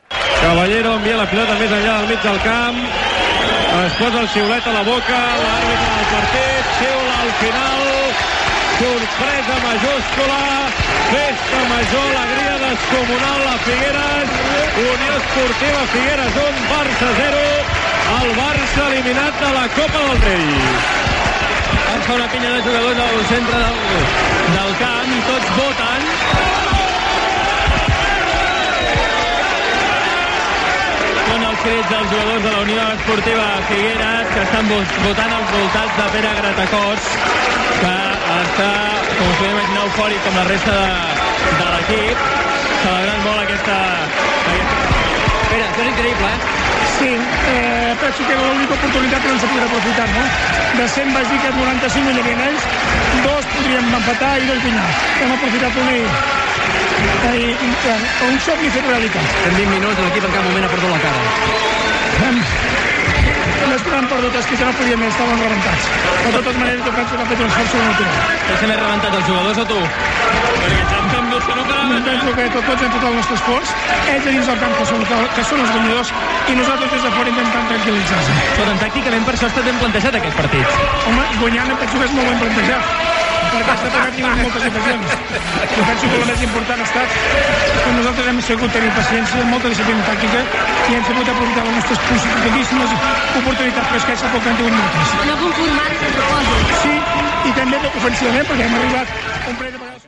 Transmissió dels 32ens de final de la Copa del Rei de futbol masculí entre la Unió Esportiva Figueres i el Futbol Club Barcelona.
Esportiu